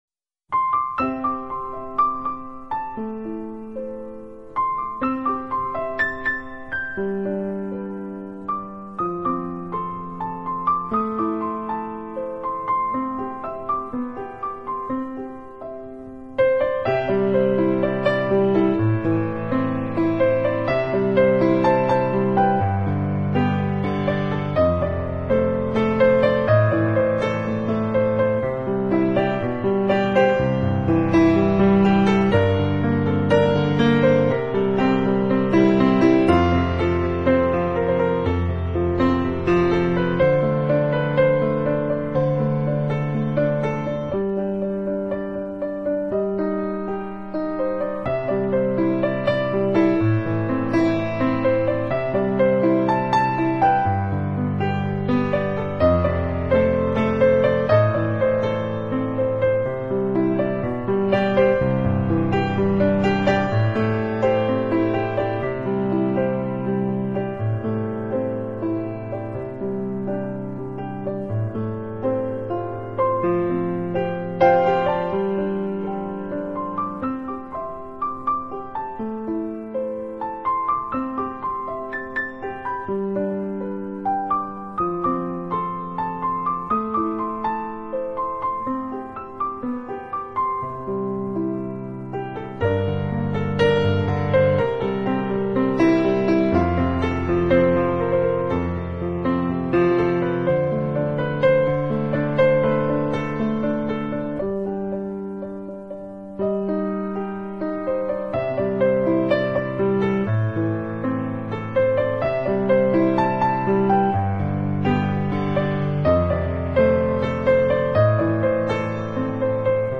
【钢琴专辑】
版本：静钢琴